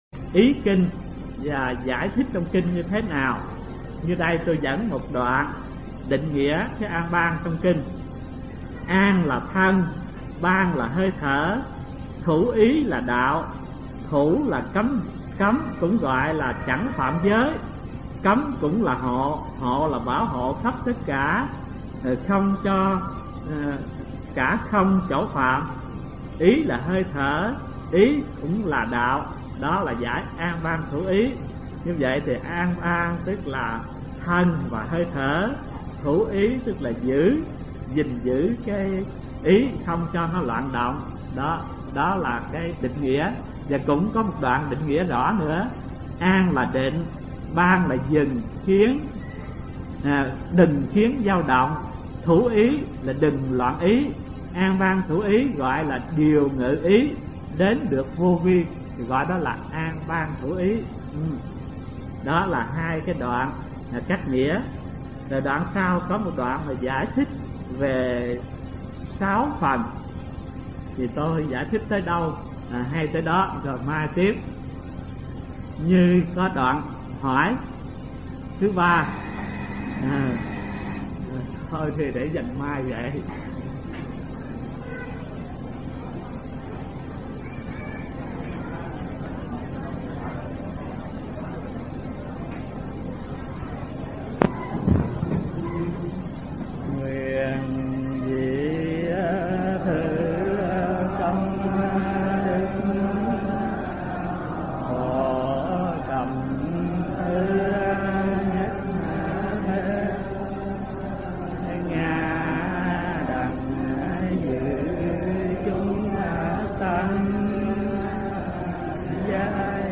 Mp3 Pháp Âm Thiền Học Phật Giáo Việt Nam 11 – Trích Đoạn Kinh An Ban Thủ Ý – Hòa Thượng Thích Thanh Từ giảng tại trường Cao Cấp Phật Học Vạn Hạnh, từ năm 1989 đến năm 1991